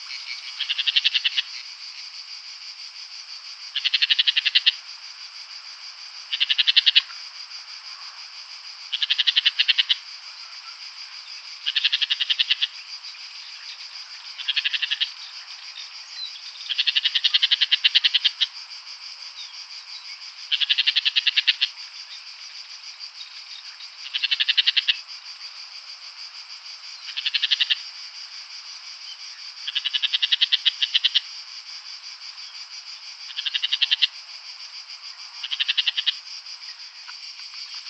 Lanius senator - Woodchat shrike - Averla capirossa